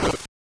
snd_wing_ch1.wav